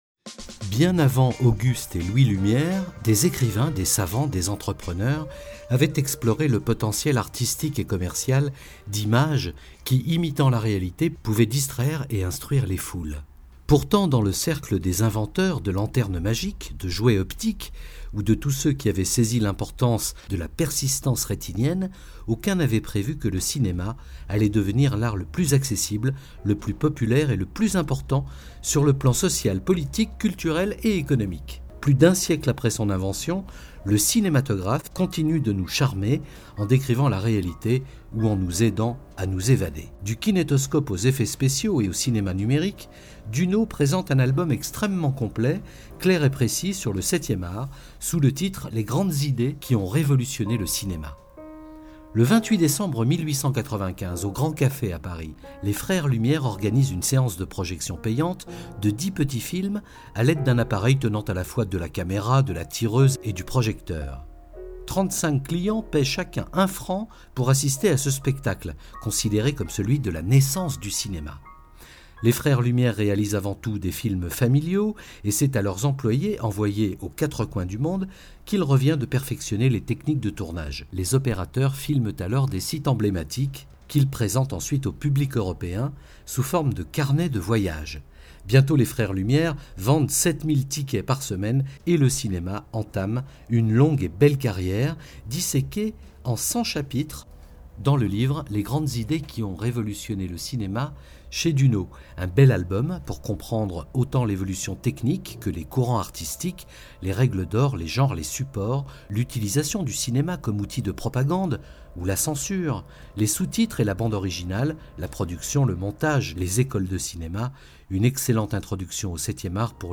Émission Radio
Interview sur LYON 1ere